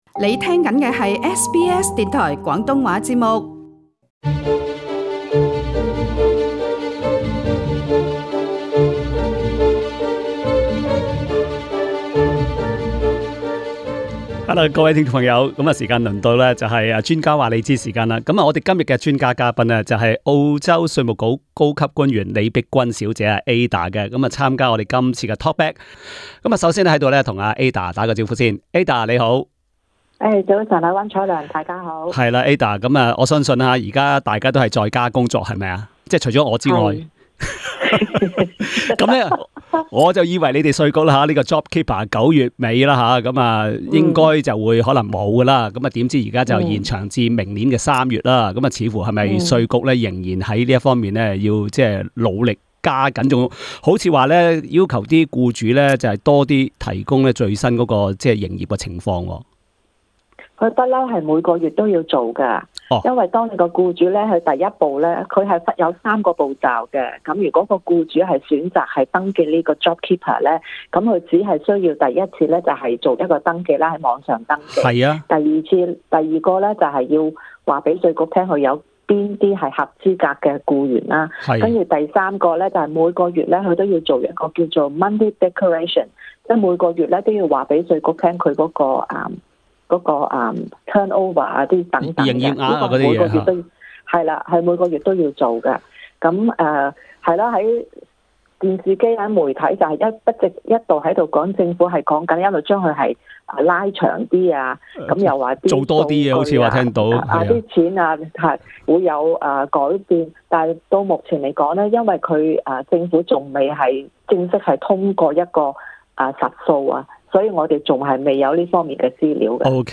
之後她還回答各位聽眾的提問。